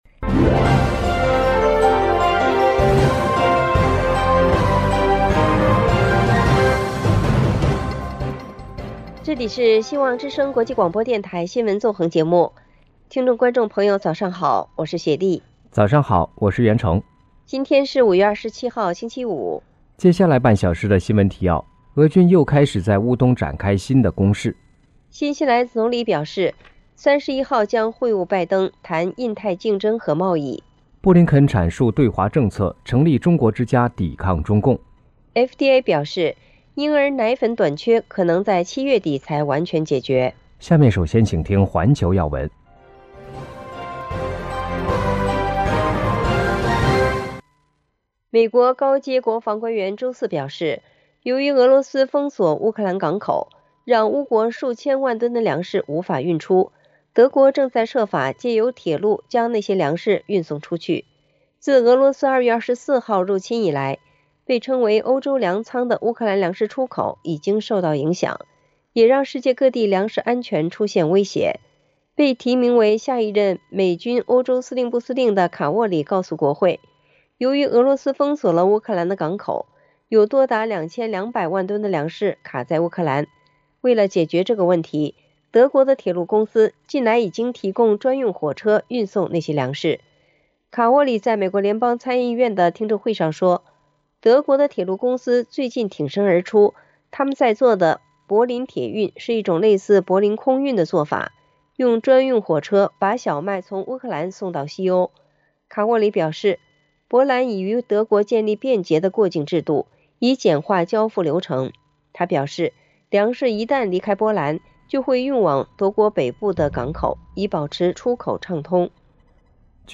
共和党提法案：将禁谷歌苹果应用商店接受数字人民币【晨间新闻】